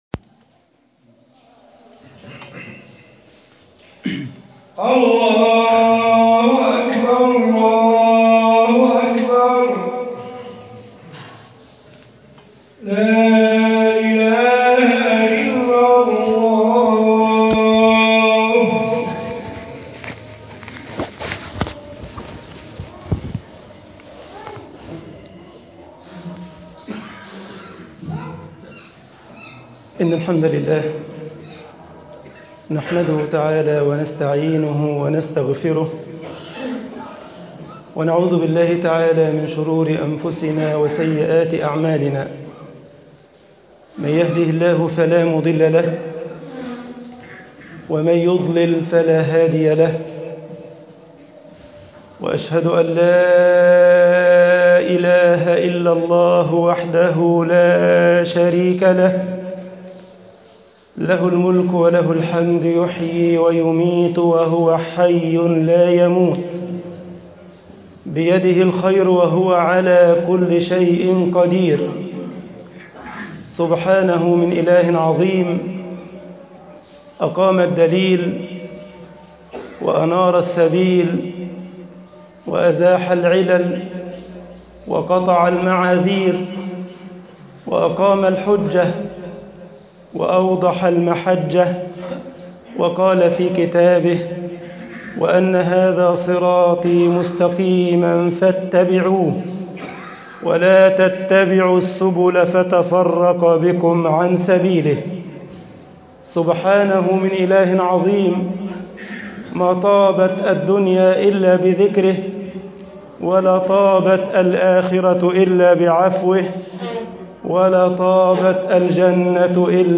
خطب الجمعة - مصر الاستجابة لله وللرسول حياة
khutab aljumaa misr 2_alistijabah lillah walirrasul hayat.mp3